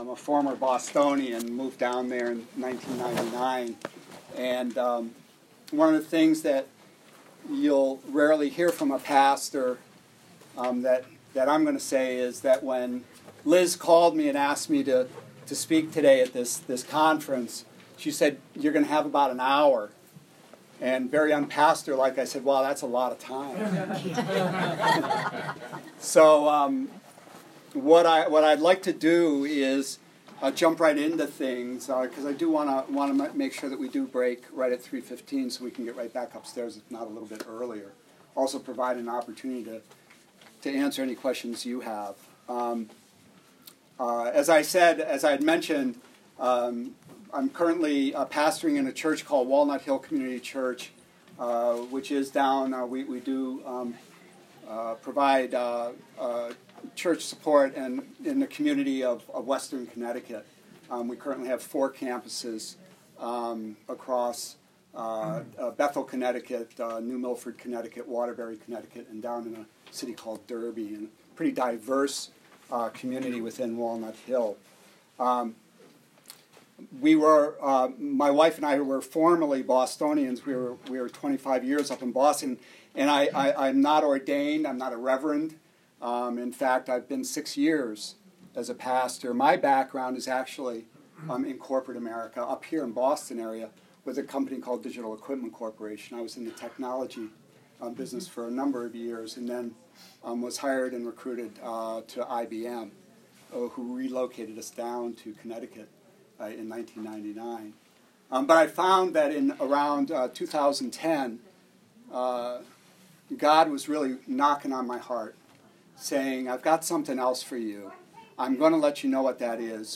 Disability and the Gospel Conference